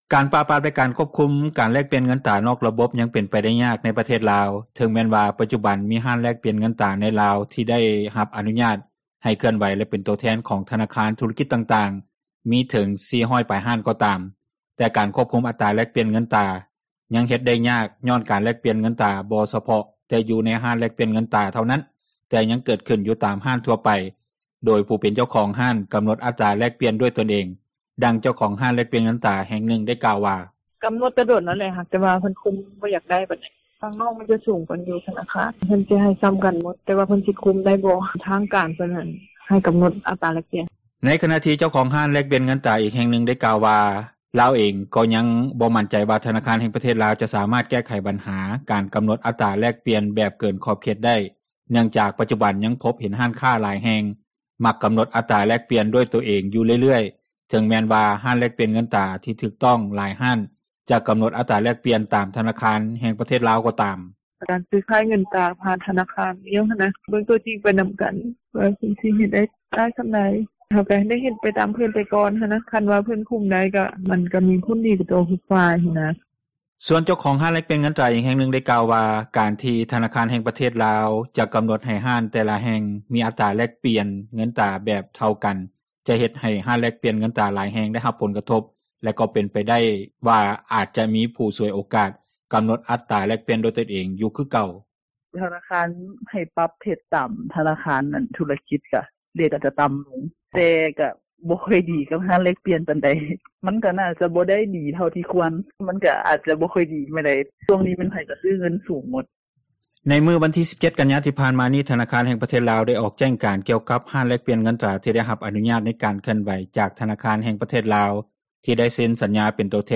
ການປາບປາມແລະຄວບຄຸມ ການແລກປ່ຽນເງິນຕຣານອກລະບົບ ຍັງເປັນໄປໄດ້ຍາກໃນປະເທດລາວ ເຖິງແມ່ນວ່າປັດຈຸບັນ ມີຮ້ານ ແລກປ່ຽນເງິນຕຣາໃນລາວ ທີ່ໄດ້ຮັບອະນຸຍາດໃຫ້ເຄື່ອນໄຫວ ແລະເປັນໂຕແທນ ຂອງທະນາຄານທຸຣະກິຈຕ່າງໆ ມີເຖິງ 400 ປາຍ ຮ້ານກໍຕາມ ແຕ່ການຄວບຄຸມ ອັດຕຣາແລກປ່ຽນເງິນຕຣາ ຍັງເຮັດໄດ້ຍາກ ຍ້ອນການແລກປ່ຽນເງິນຕຣາ ບໍ່ສະເພາະແຕ່ ຢູ່ໃນຮ້ານ ແລກປ່ຽນເງິນຕຣາເທົ່ານັ້ນ ແຕ່ຍັງເກີດຂຶ້ນຢູ່ຕາມຮ້ານທົ່ວໄປ ໂດຍຜູ້ເປັນເຈົ້າຂອງຮ້ານ ກຳນົດອັດຕຣາແລກປ່ຽນດ້ວຍຕົນເອງ. ດັ່ງ ເຈົ້າຂອງຮ້ານແລກປ່ຽນເງິນຕຣາ ແຫ່ງນຶ່ງກ່າວວ່າ: